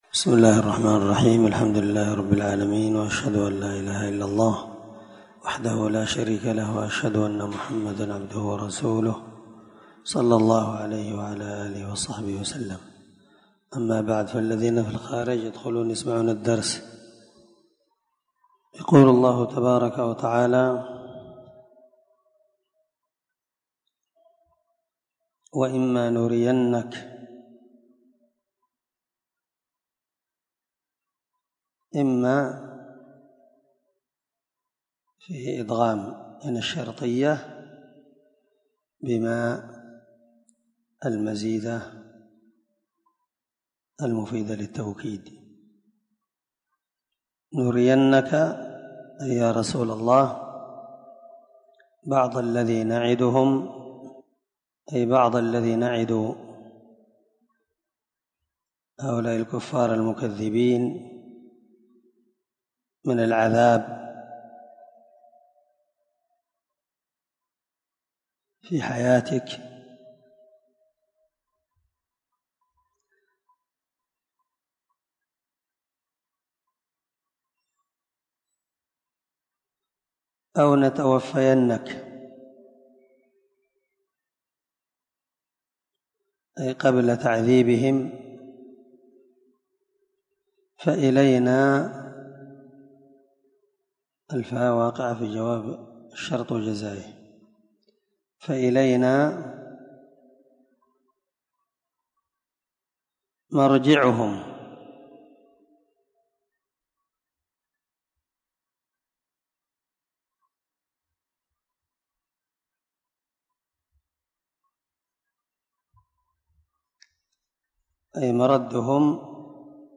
601الدرس 17 تفسير آية ( 46- 49) من سورة يونس من تفسير القران الكريم مع قراءة لتفسير السعدي